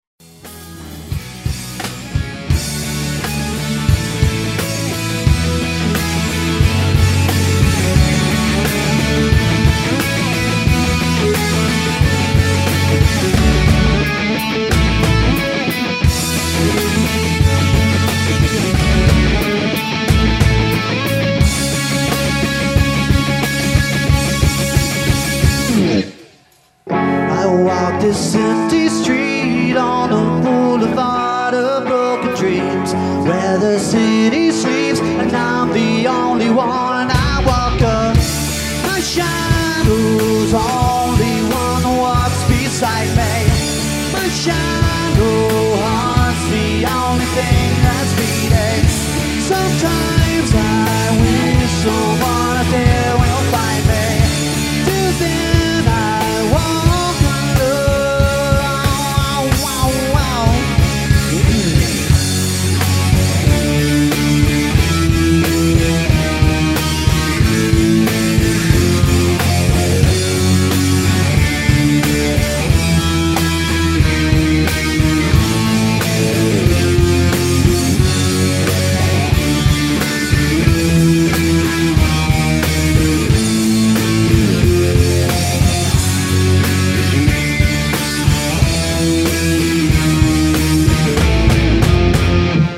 live demo clips from 2004
These clips were recorded at our first show (I know, right?
drums and vocals
bass and vocals
guitar
keys and vocals
lead vocals